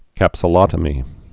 (kăpsə-lŏtə-mē)